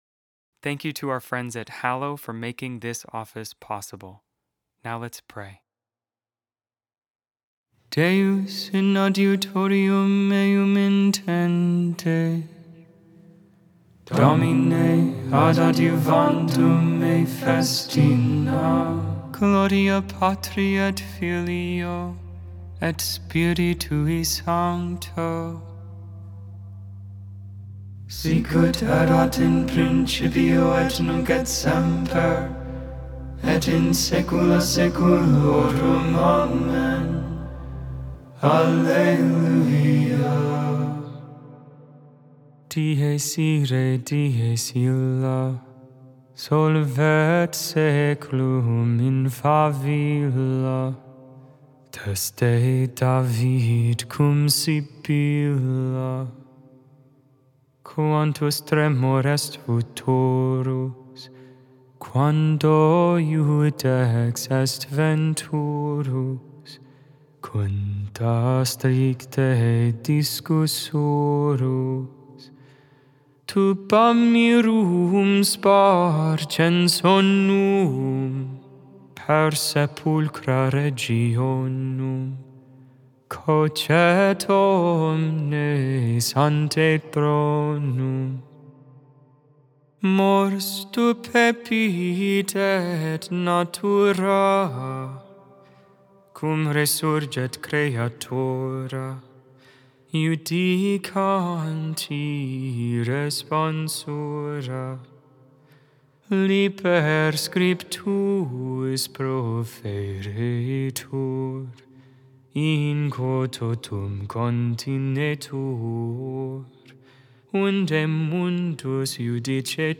Vespers, Evening prayer for the 32nd Thursday in Ordinary Time, November 13, 2025.Memorial of St. Frances Xavier CabriniMade without AI. 100% human vocals, 100% real prayer.